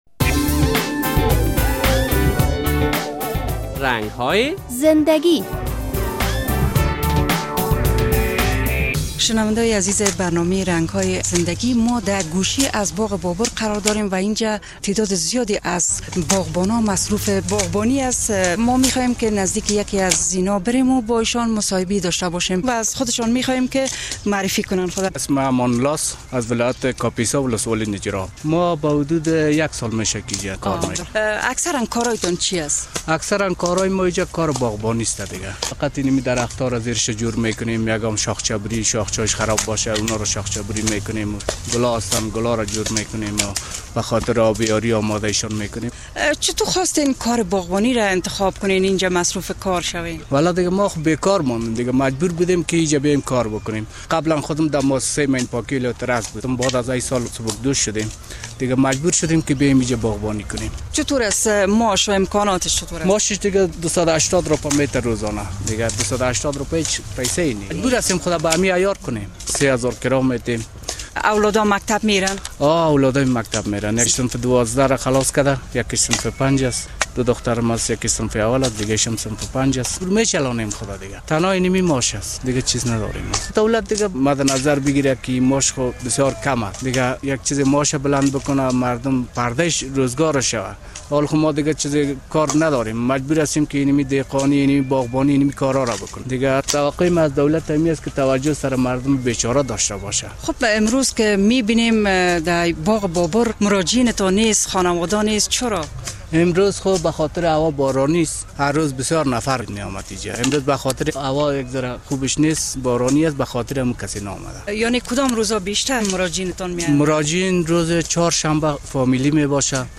در این برنامهء رنگ های زندگی خبرنگار رادیو آزادی با یک تن از باغبانان باغ بابر صحبت شده است.